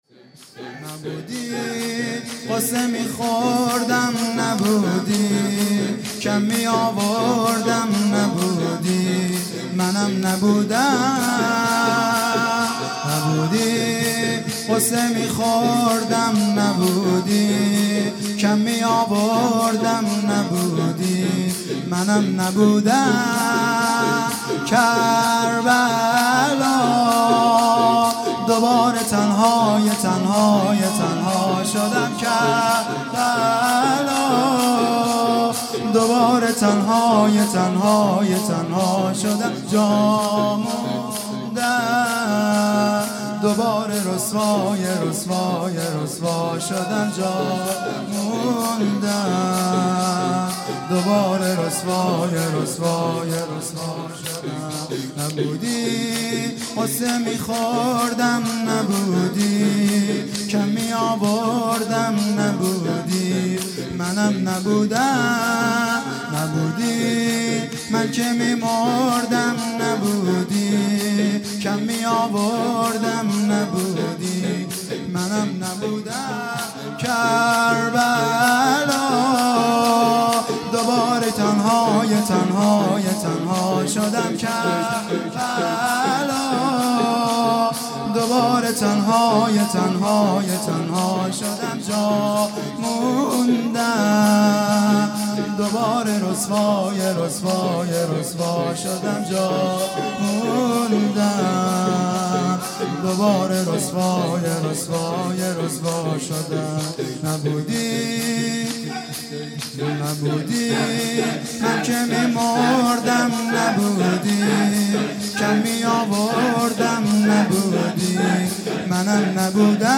شور
دوباره تنهای تنها شدم|جلسه هفتگی ۱۶ آذر ۹۵